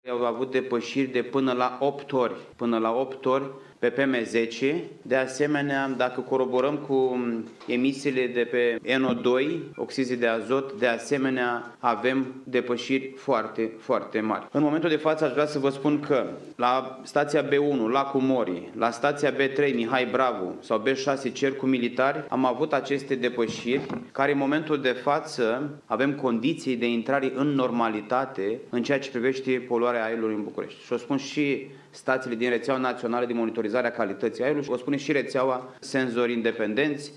Ministrul Mediului, Costel Alexe, a declarat după o şedinţă cu mai mulţi responsabili în domeniu, că în acest moment, calitatea aerului se îmbunătăţeşte, deşi cantitatea de praf foarte fin din aer încă este peste limitele admise: